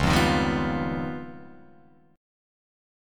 C#9b5 chord